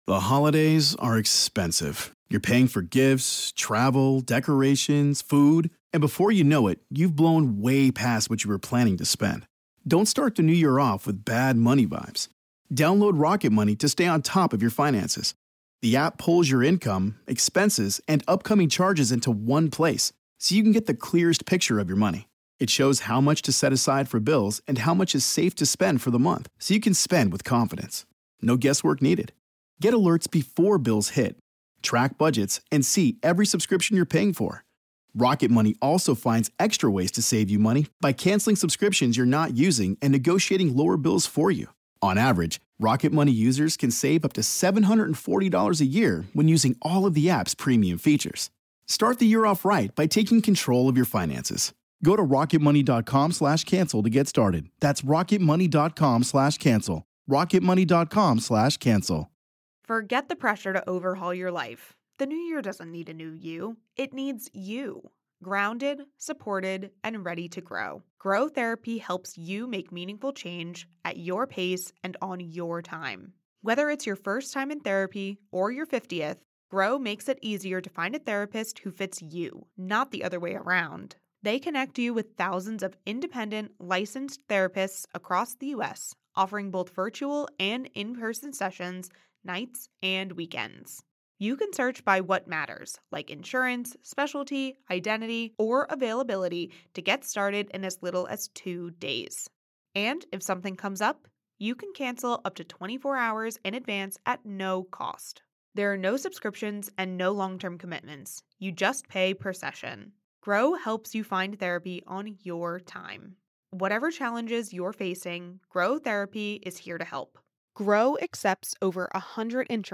Through in-depth reporting and survivor testimony, this season explores the origins, evolution, and current failures of Title IX, the landmark federal civil rights law designed to protect students from sex-based discrimination i